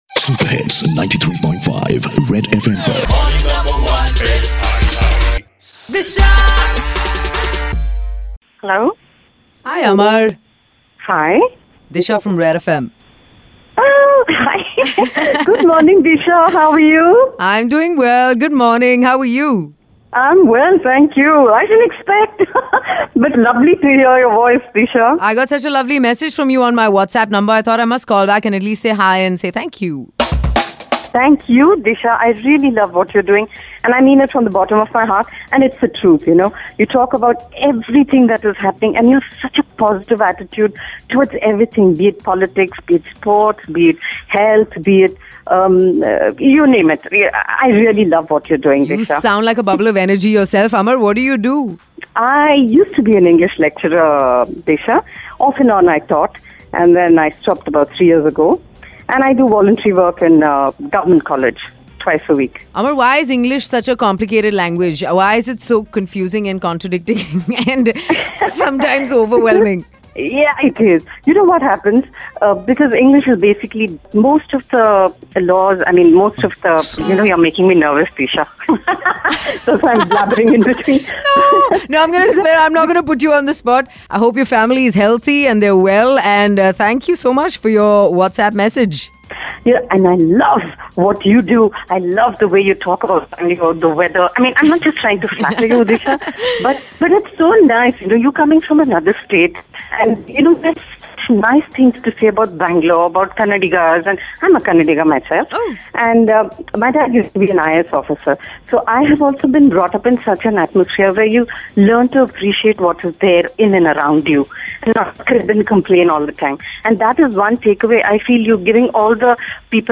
Home is India! Tune in to this super sweet conversation